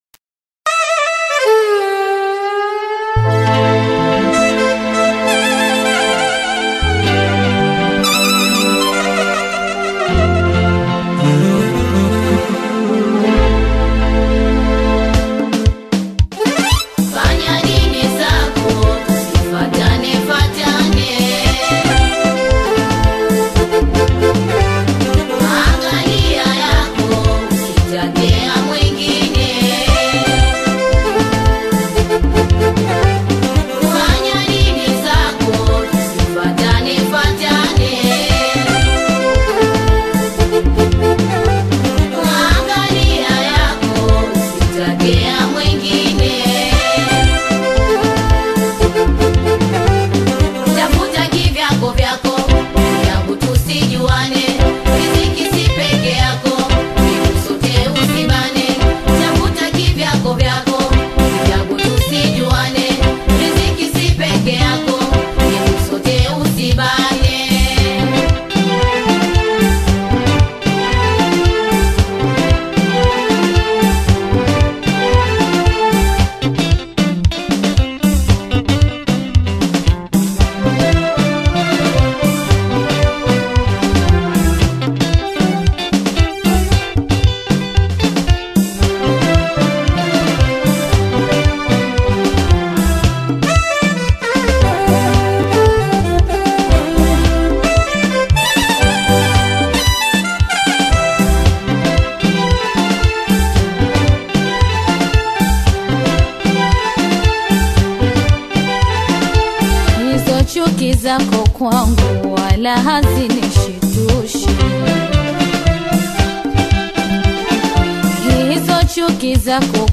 powerful live instrumentals
sweet and expressive vocals